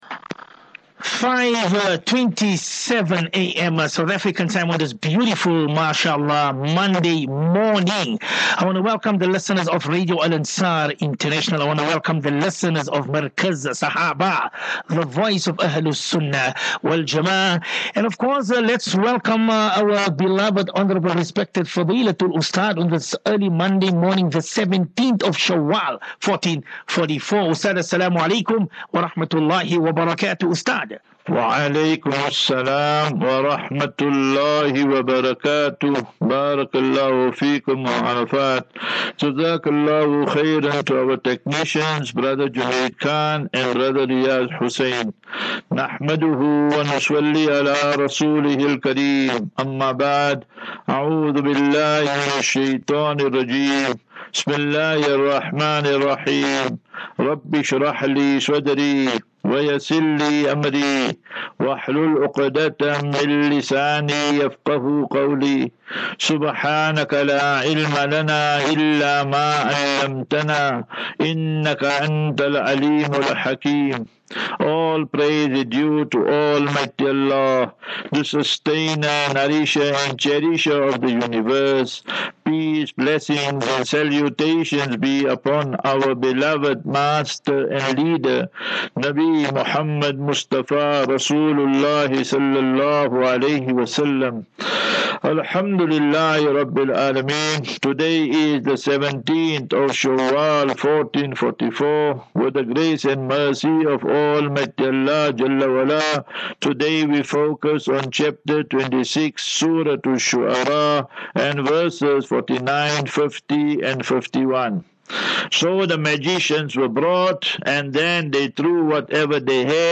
As Safinatu Ilal Jannah Naseeha and Q and A 8 May 08 May 23 Assafinatu